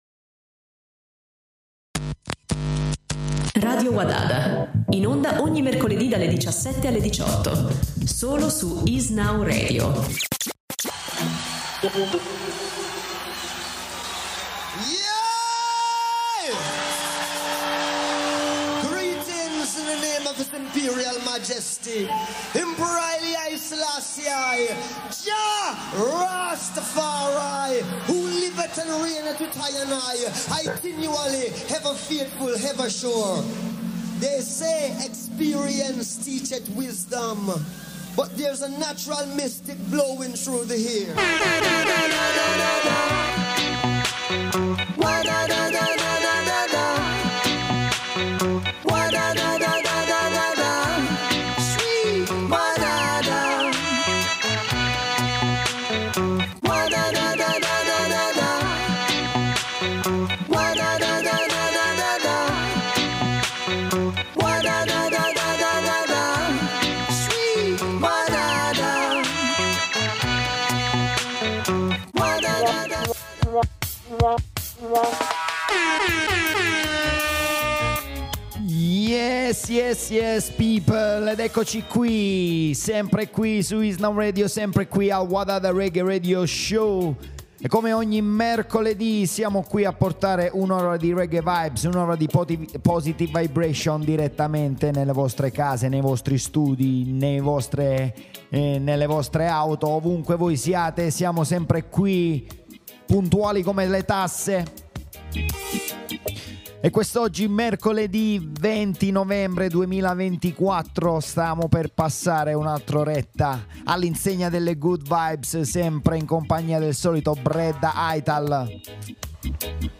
The Best Web Radio Show Of Roots Reggae Dub Music